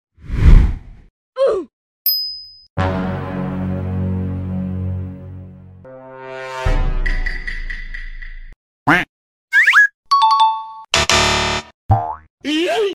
เสียงตลกๆ ใน TikTok
หมวดหมู่: เสียงประกอบ
am-thanh-hai-huoc-trong-tiktok-www_tiengdong_com.mp3